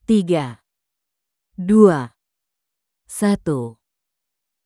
countdown321.wav